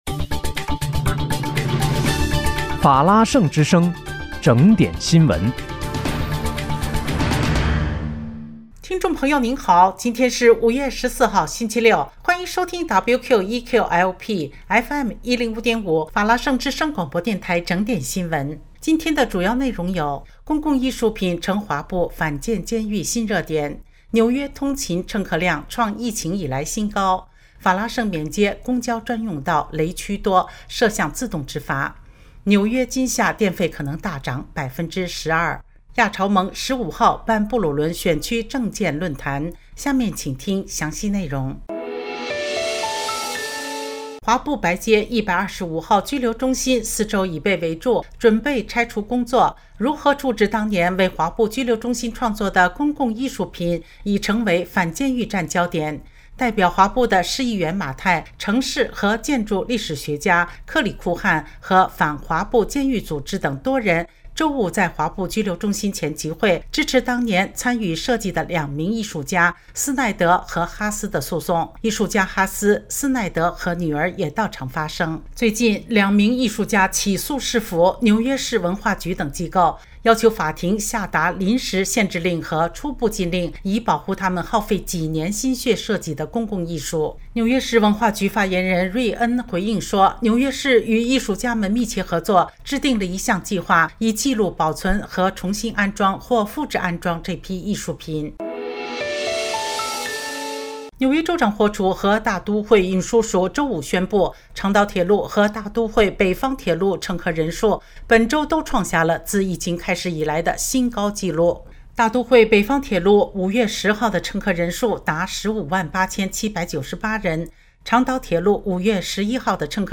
5月14日（星期六）纽约整点新闻
听众朋友您好！今天是5月14号，星期六，欢迎收听WQEQ-LP FM105.5法拉盛之声广播电台整点新闻。